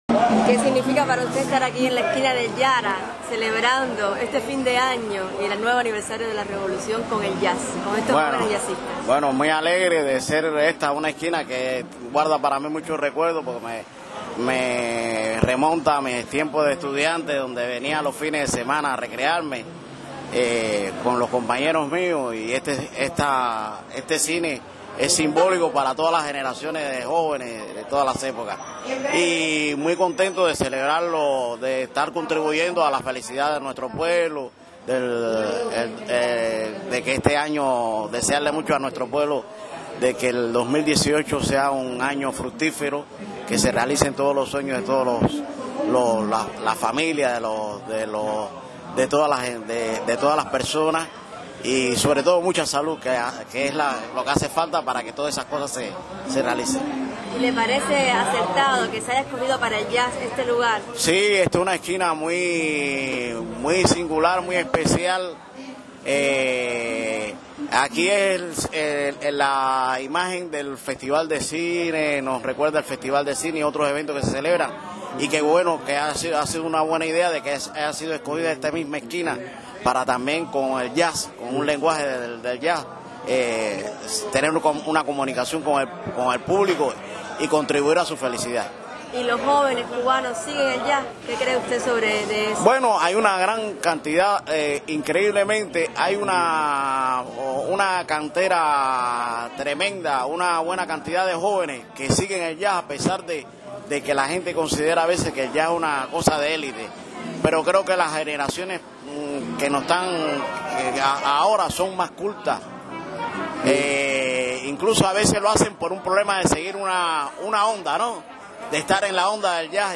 Sobre la relevancia de ese suceso; la manera en que los jóvenes cubanos siguen el Jazz y sus consideraciones acerca del Concurso JoJazz 2017, el Maestro accedió a conversar con Radio Reloj.